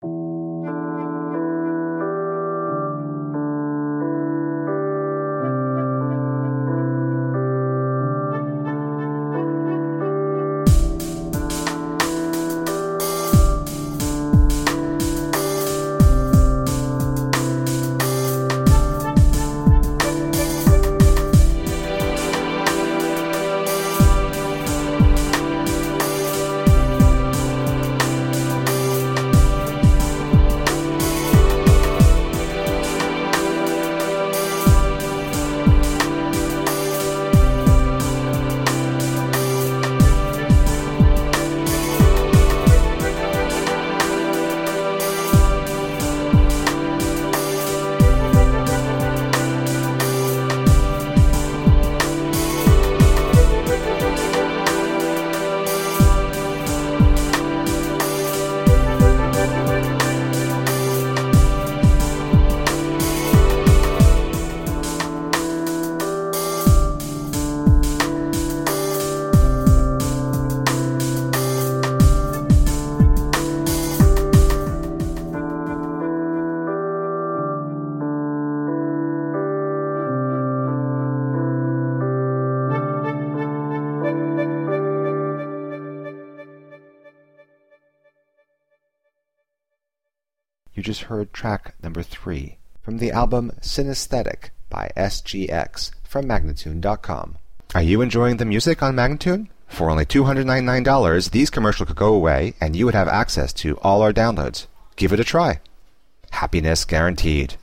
Straddling several electronica genres
Tagged as: Electro Rock, Ambient